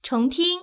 ivr-repeat_this_information.wav